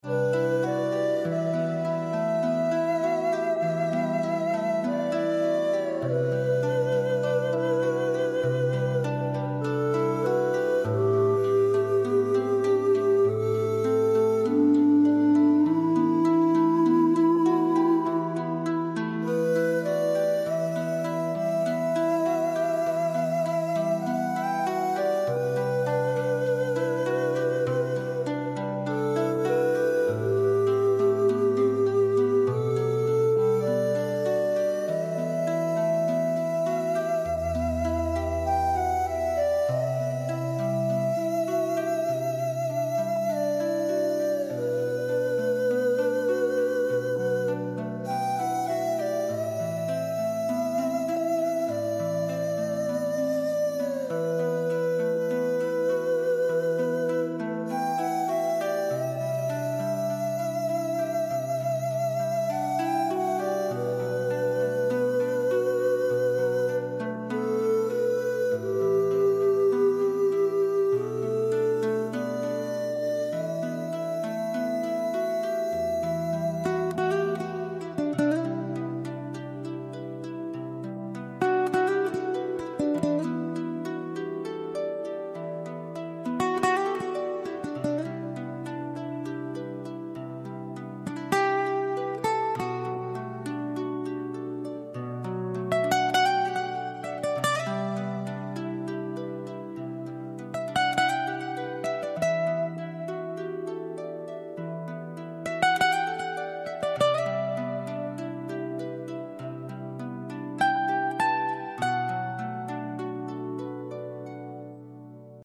888 hz | Step into tranquility.